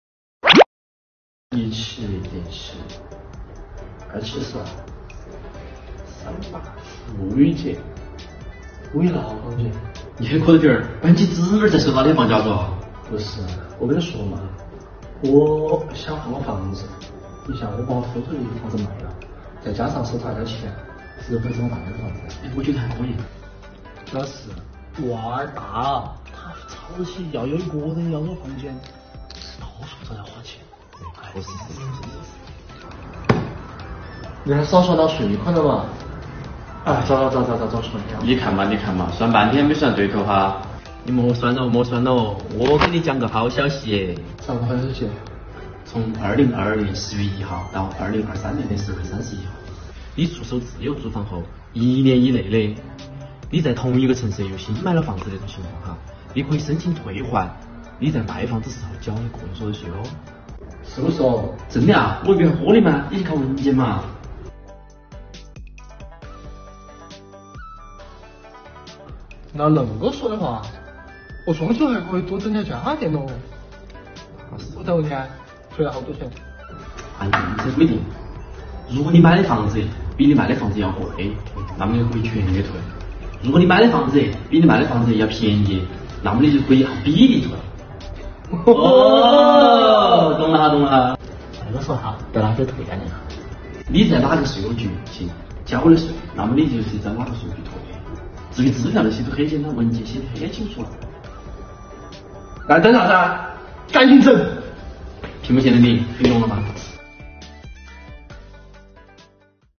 重庆言子来了④ | 购房新政来啦！